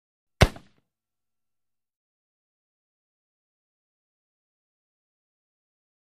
Musket: Single Fire; Single Shot Of Musket Fire. Crunchy, High Pitched Slightly Muted Sounding Shots At Medium Close Perspective. Gunshots.